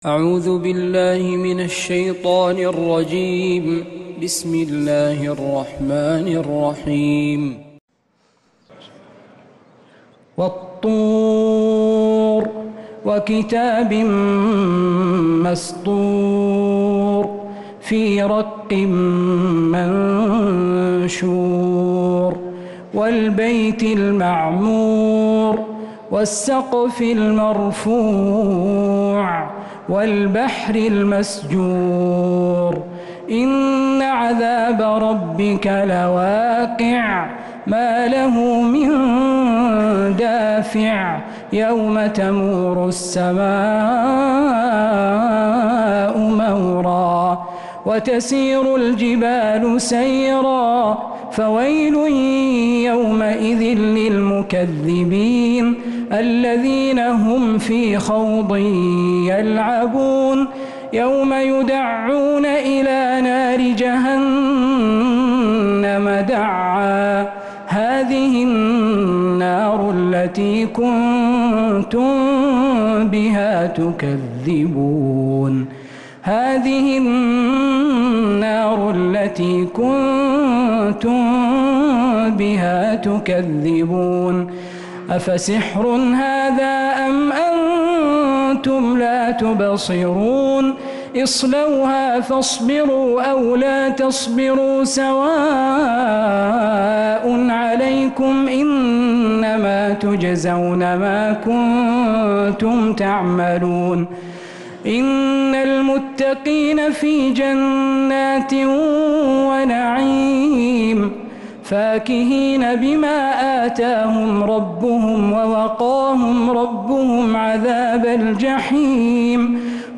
سورة الطور من تراويح الحرم النبوي